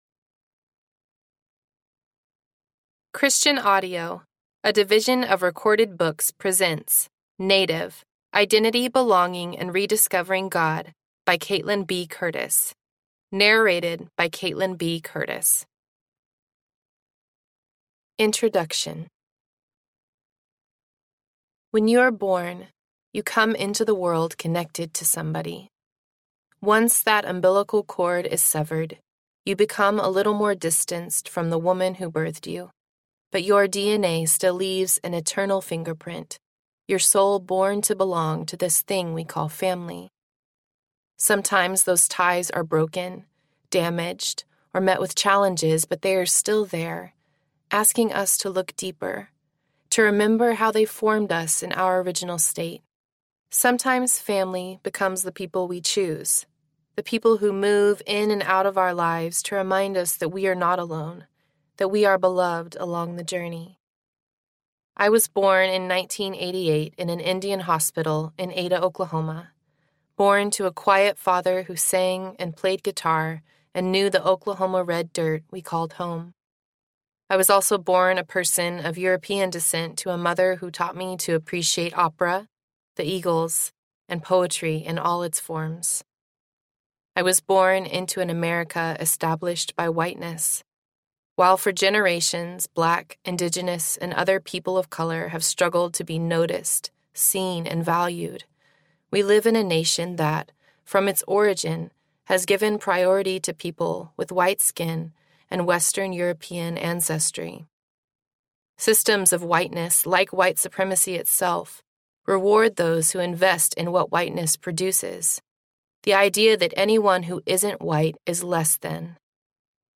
Native Audiobook
5.7 Hrs. – Unabridged